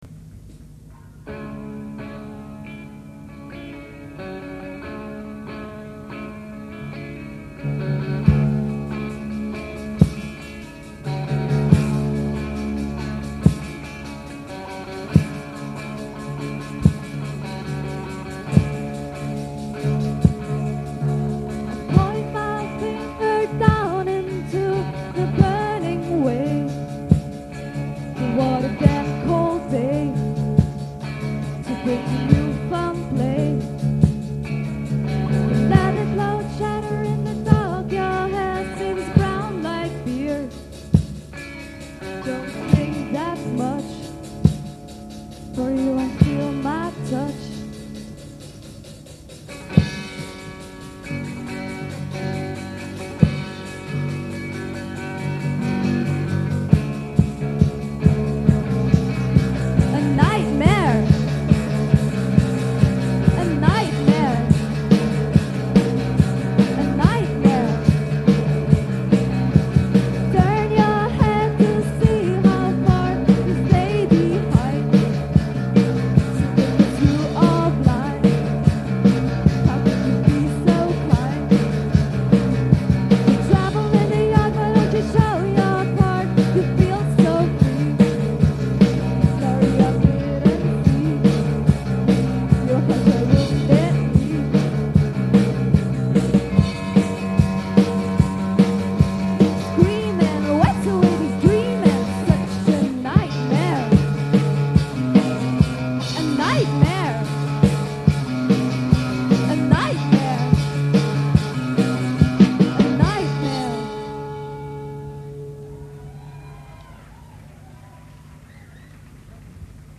Op 16 maart 1993 speelden we live op het "boerenkot" te Gent. Het was ook het laatste optreden.
gitaar
basgitaar
zang